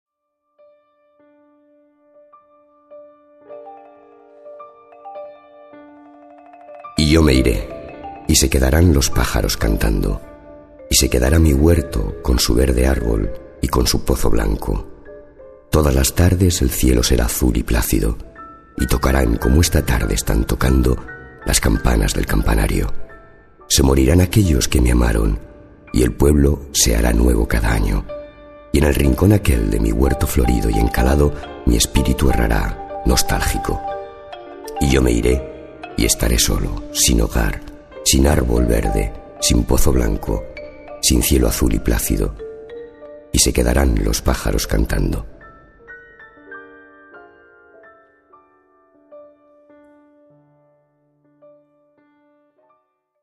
voz grave, adulto, constitucional
Sprechprobe: eLearning (Muttersprache):